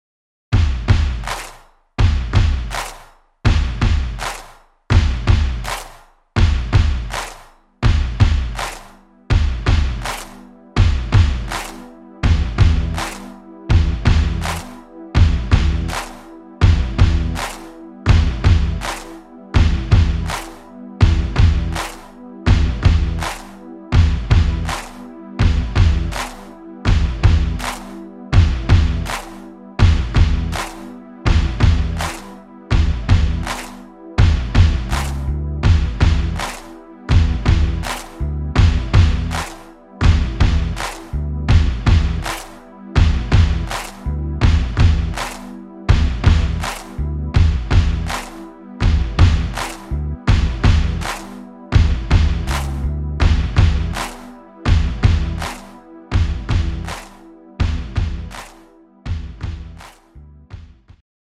Rhythmus  Rock
Art  Rock, Englisch, Pop